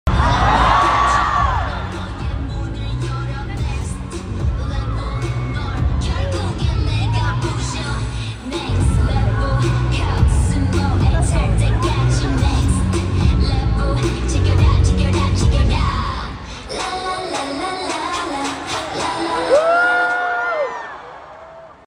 Live Tour
in Seoul